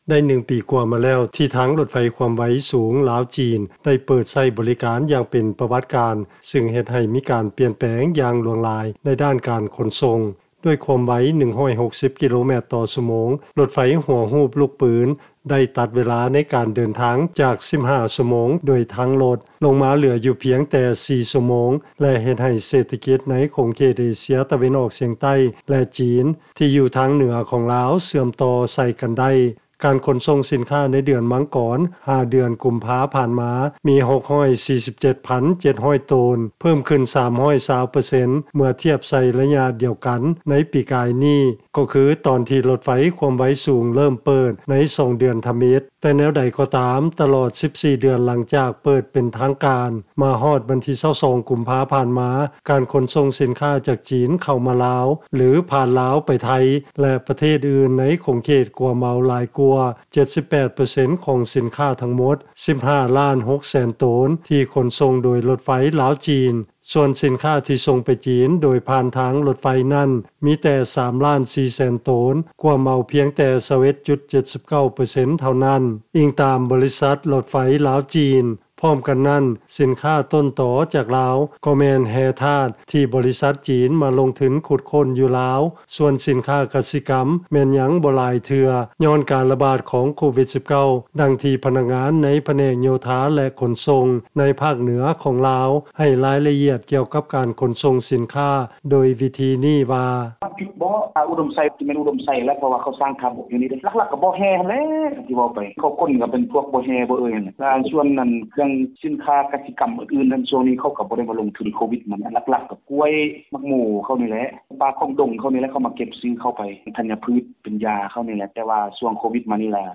ຟັງລາຍງານ ການຂົນສົ່ງຜ່ານທາງລົດໄຟລາວ-ຈີນ ເພີ້ມຂຶ້ນຫລາຍ ໃນ 1 ປີກວ່າຜ່ານມາ ແຕ່ສ່ວນໃຫຍ່ແມ່ນຂົນສົ່ງສິນຄ້າຈີນ ເຂົ້າມາລາວຫລາຍກວ່າ